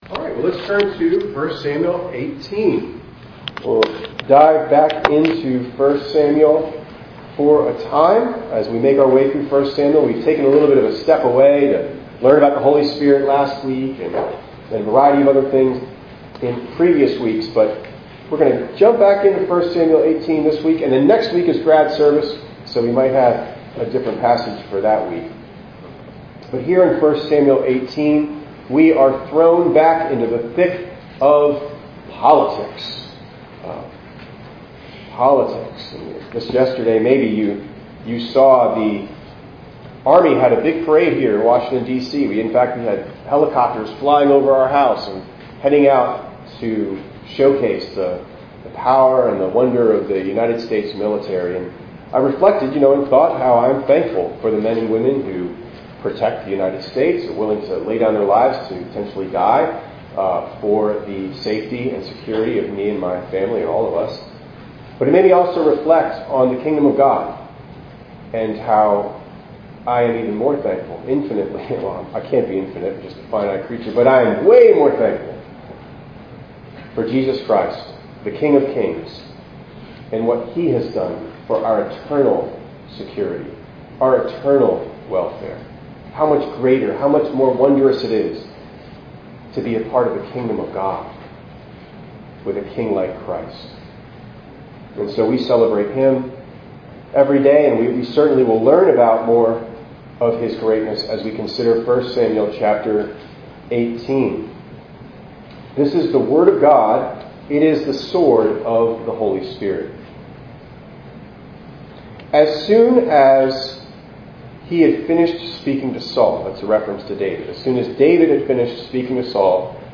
6_15_25_ENG_Sermon.mp3